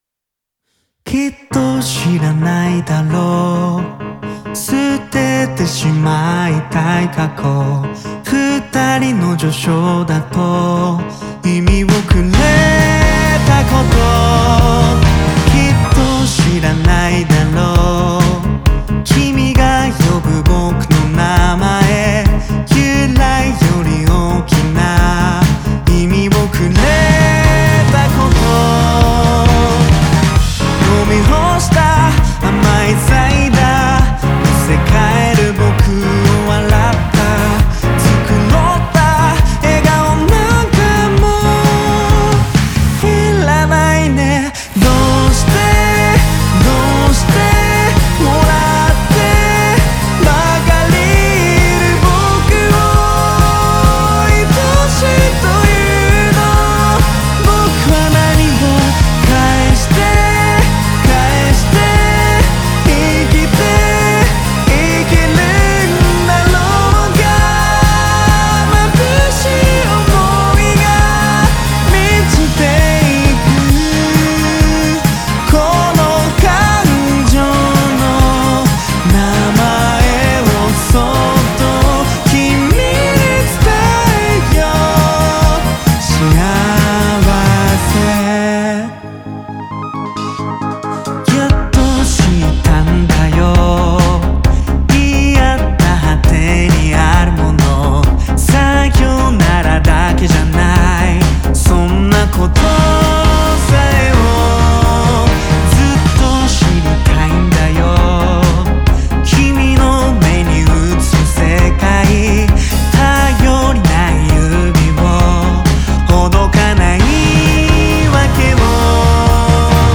Genre : J-Pop.